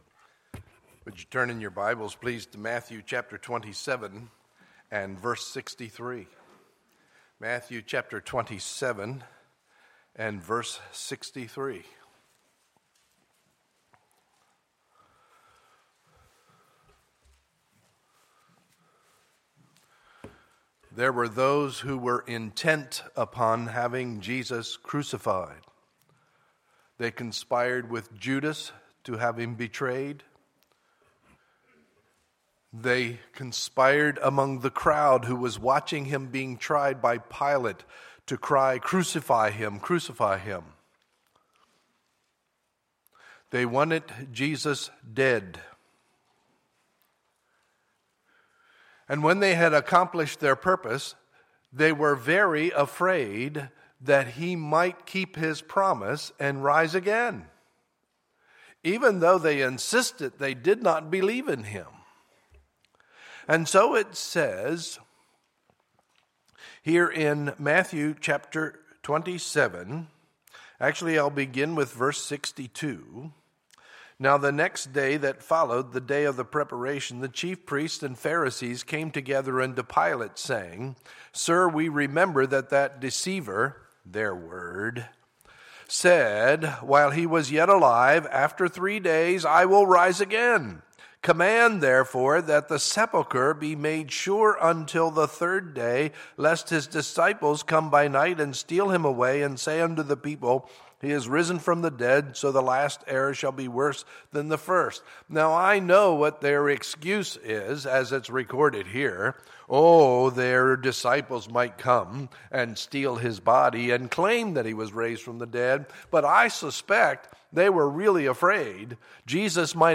Sunday, April 5, 2015 – Sunday Morning Service